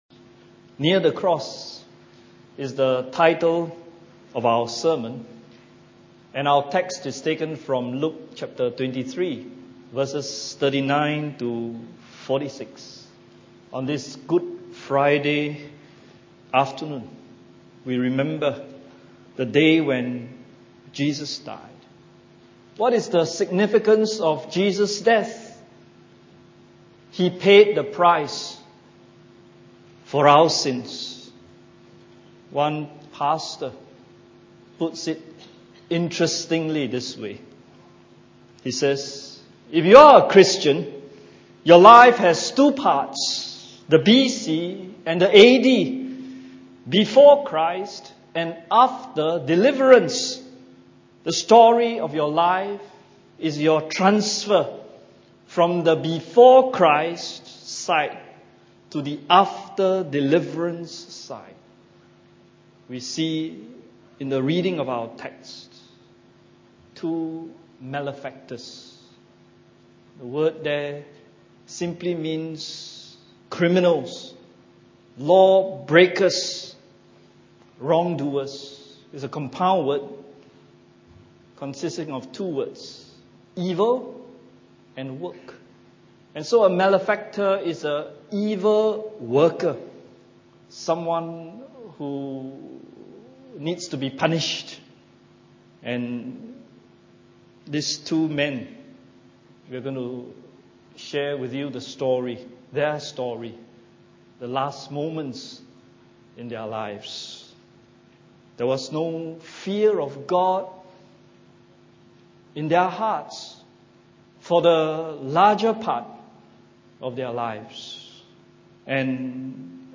Good Friday Service 2015 – Near the Cross